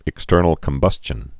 (ĭk-stûrnəl-kəm-bŭschən)